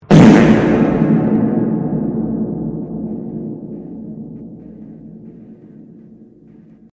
Loud Fart Sound Effect Free Download
Loud Fart